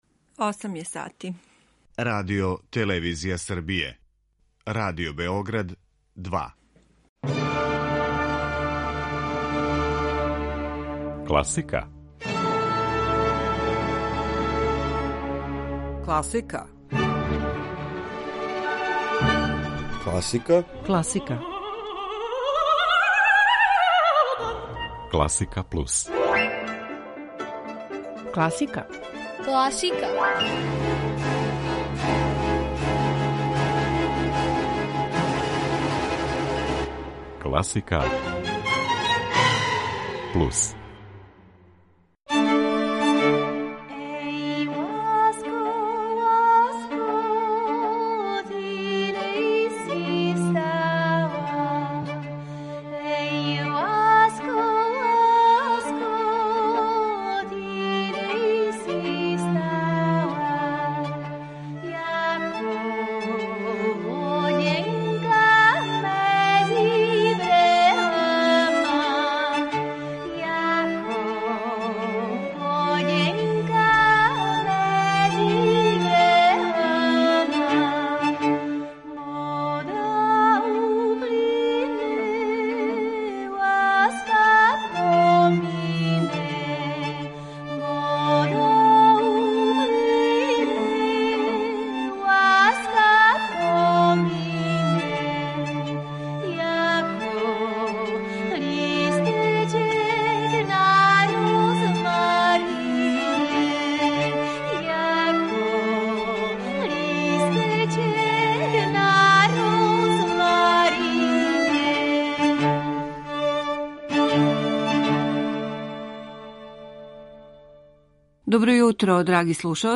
Виолисту Јурија Башмета и његово тумачење музике Јоханеса Брамса представљамо у рубрици „На други начин".